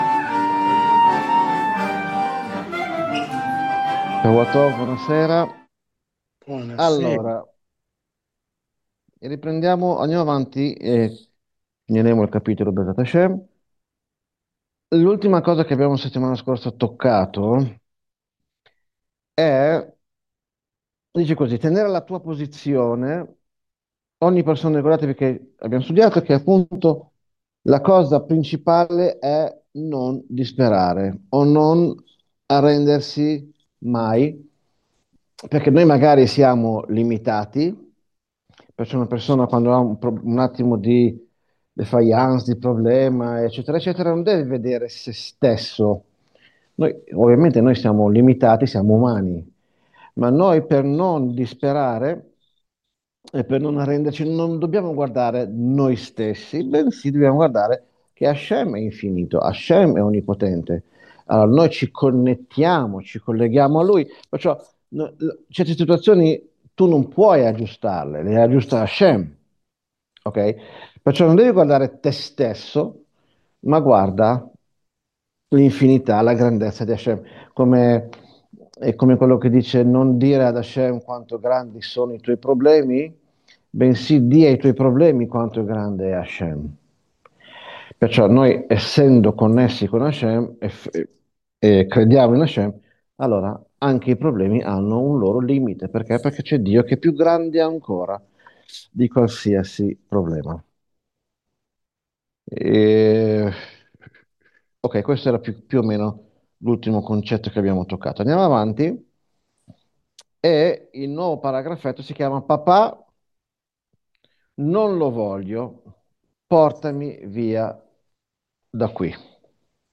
Lezione del 10 gennaio 2026